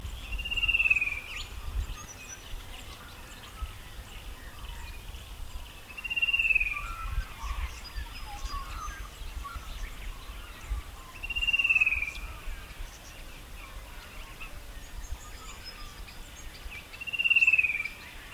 Cuckoos calling
Fan-tailed Cuckoo –
f-tail-cuckoo-sel.mp3